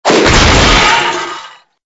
ENC_cogfall_apart.ogg